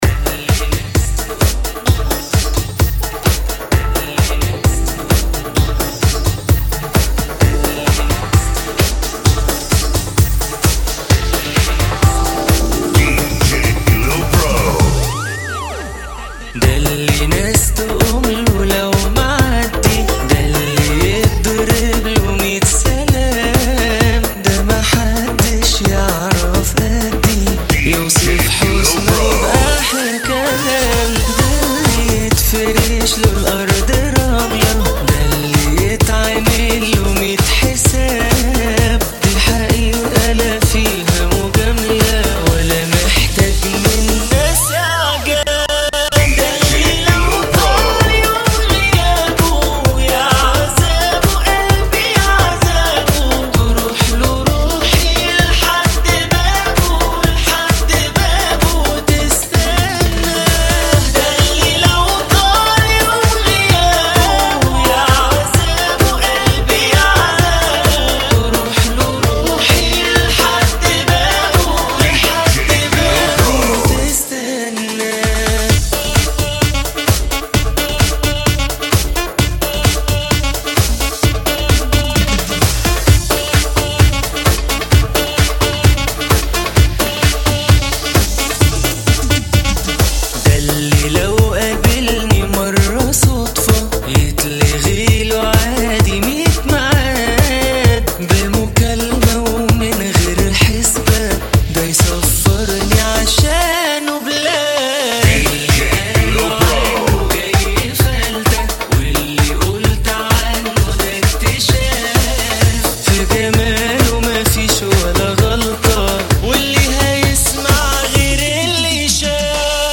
[ 130 Bpm ]